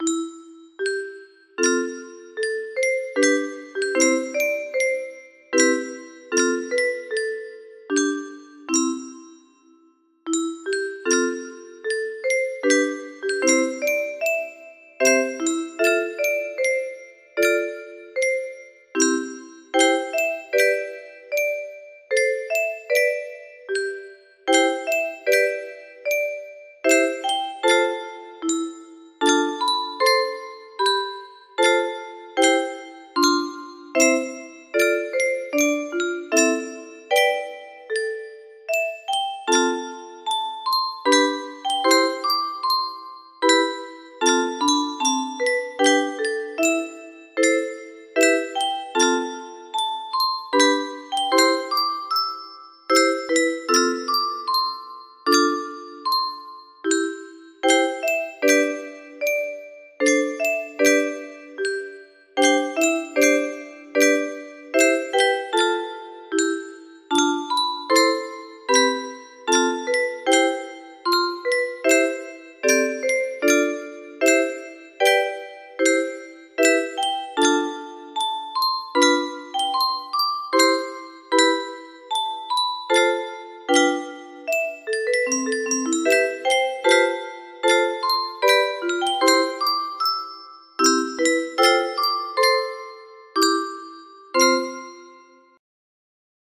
Holst Jupiter Extended music box melody